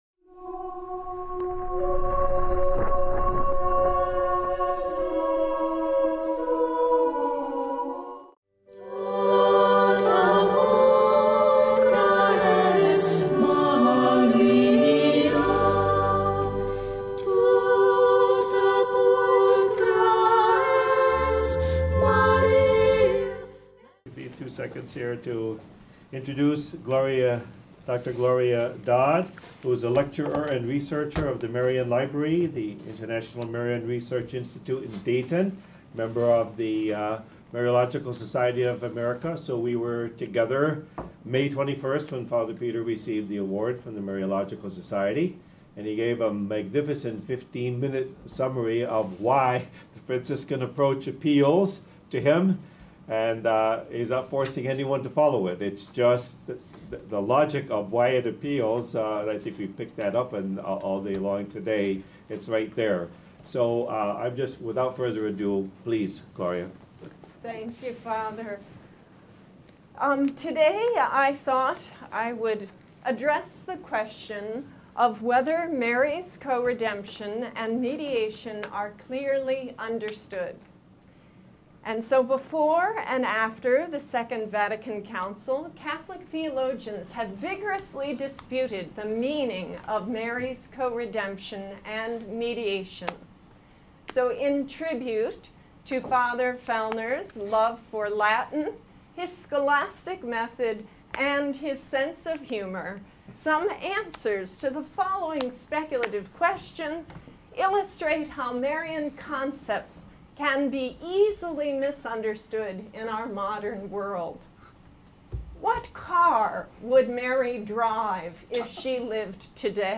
At the Symposium titled "Sursum Actio" at Notre Dame Univ.